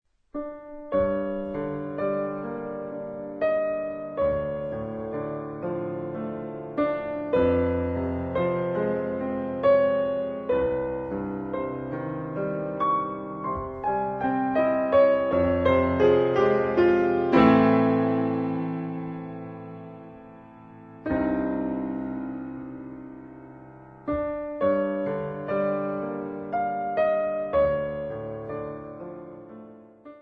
12 Klavierstücke, mittelschwer
Besetzung: Klavier